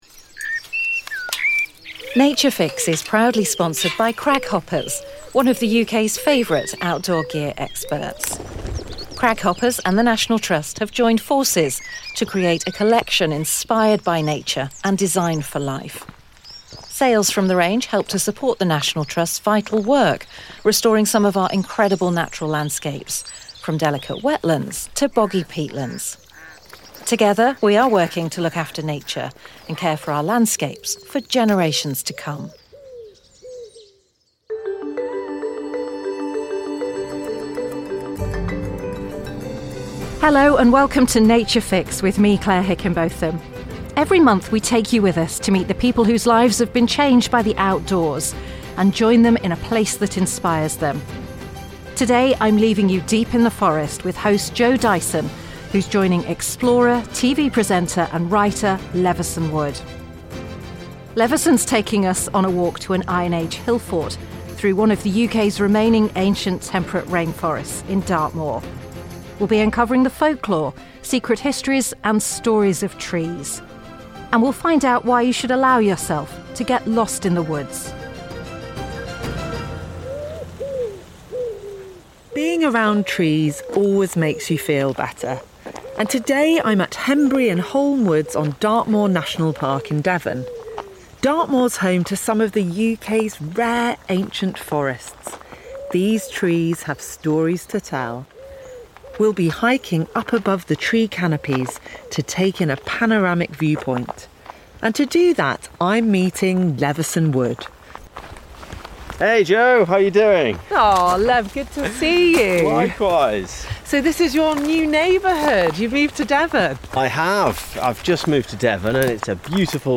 But there’s nowhere quite like home and on this journey we’re taking a summer hike through the lush oak forests of Hembury and Holne Woods in Dartmoor National Park. We explore the stories from Celtic lore held in these centuries-old woods and reflect on the amazing environmental and health benefits of trees.